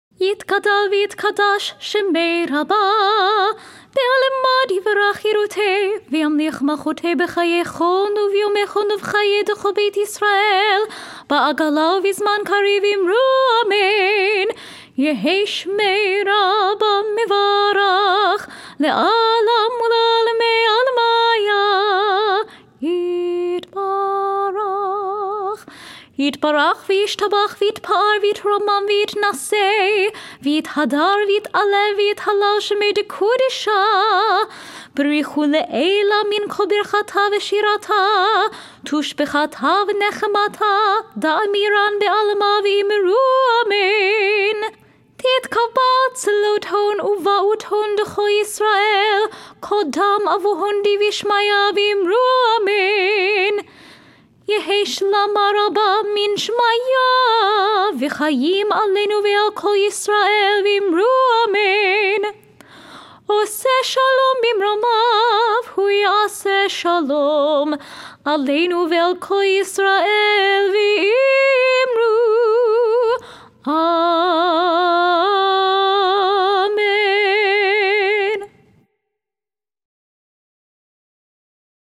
Festival Ma’ariv (Higher Voice)
Kaddish Shalem (Nusah Major)Download